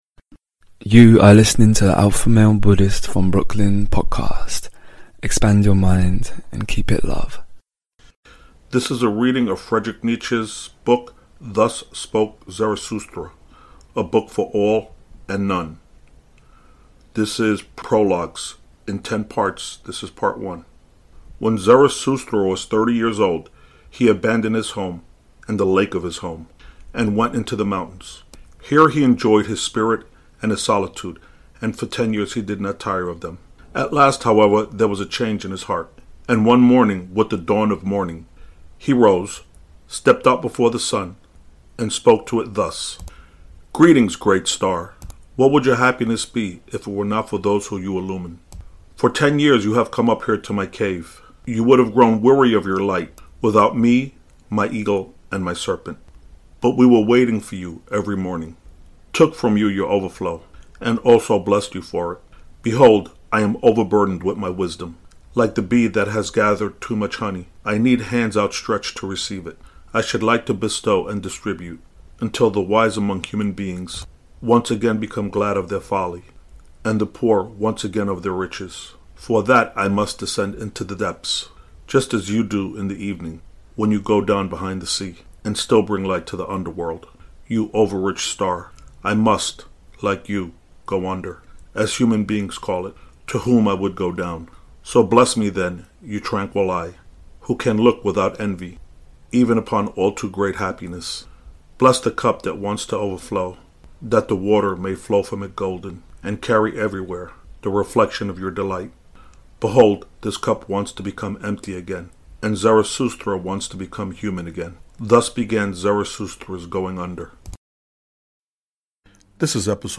Ep 129 - Friedrich Nietzsche - Thus Spoke Zarathustra - Prologue - 1 Thru 5 - Reading and commentary - Uber Mensch